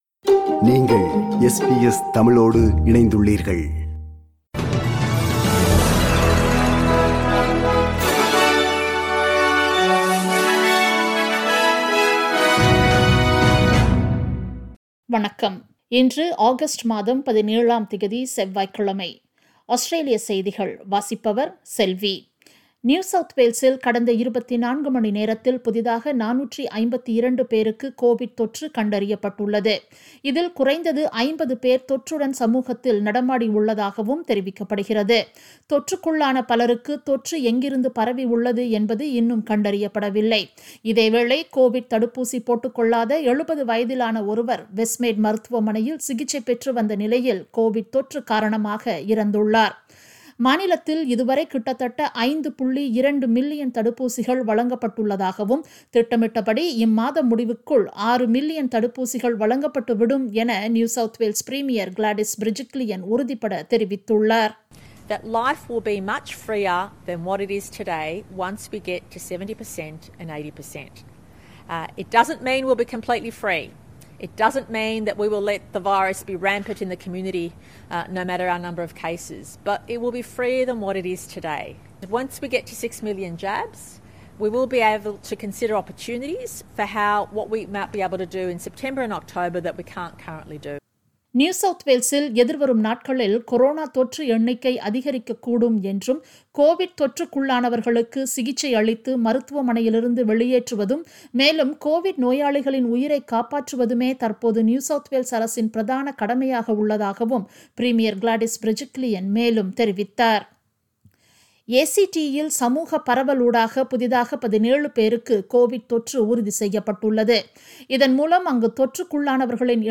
Australian news bulletin for Tuesday 17 August 2021.